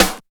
99 SNARE 3.wav